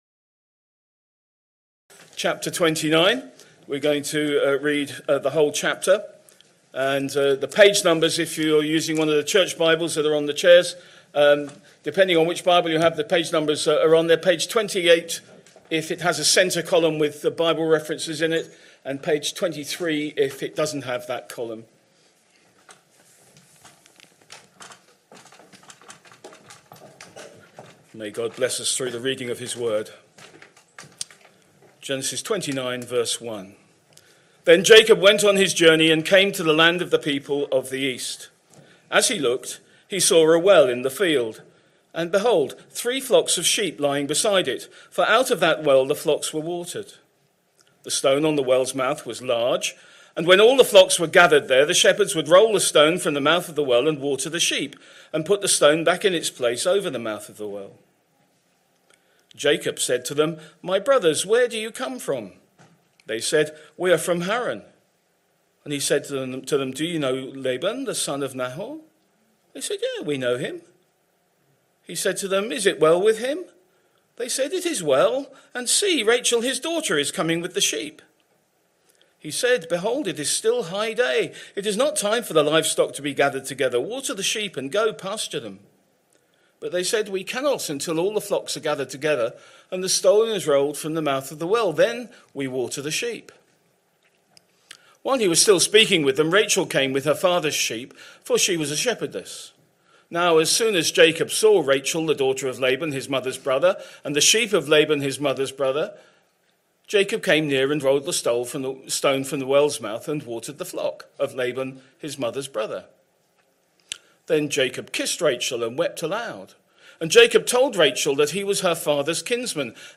Sunday Morning Service Sunday 24th August 2025 Speaker